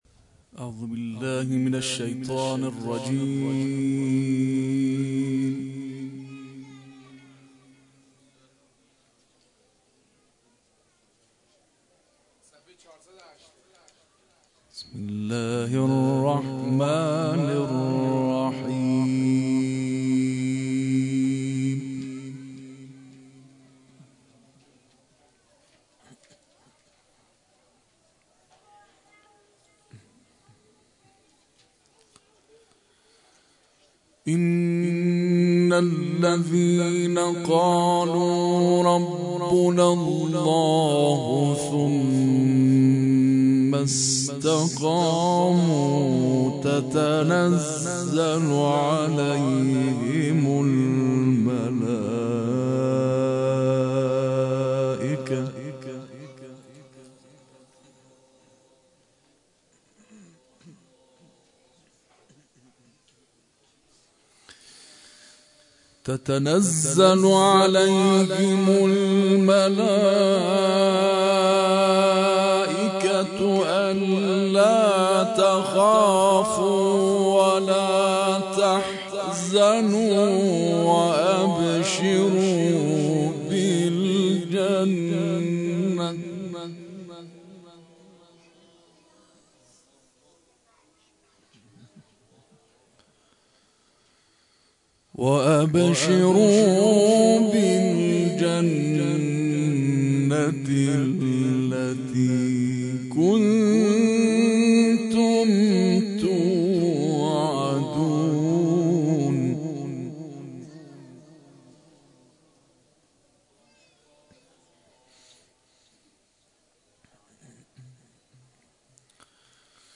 جدیدترین تلاوت
با حضور در حسینیه فاطمه الزهراء(س) به تلاوت آیاتی از کلام الله مجید پرداخت که در ادامه ارائه می‌شود.